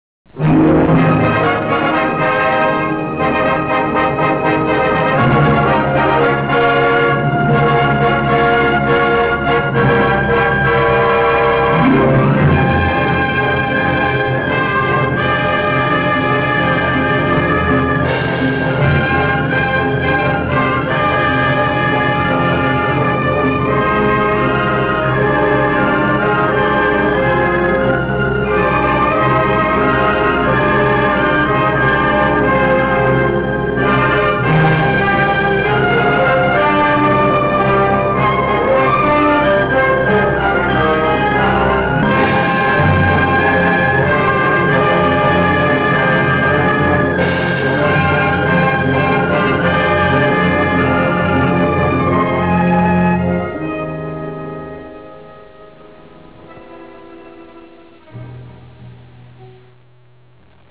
Track Music